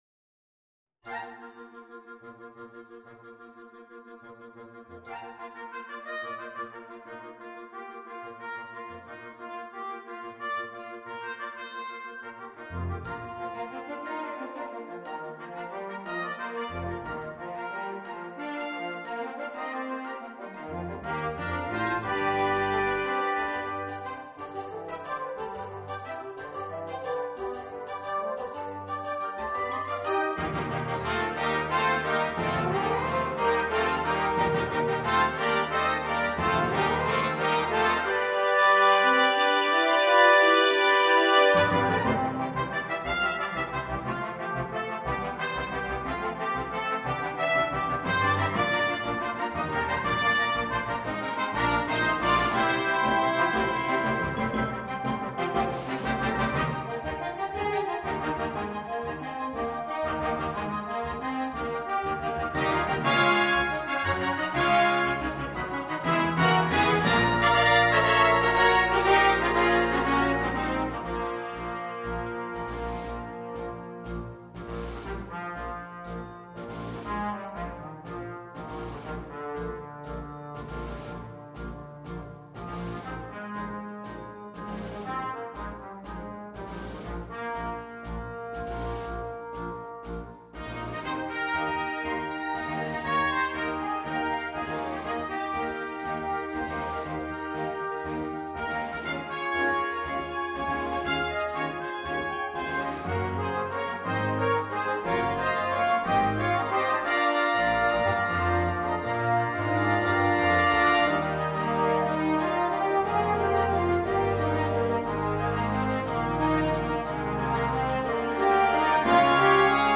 Gattung: Konzertant
Besetzung: Blasorchester
im Schlussteil alle Themen noch einmal aufgegriffen werden.